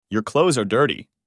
Portanto, a pronúncia será com som de /z/, ou seja, /klouz/.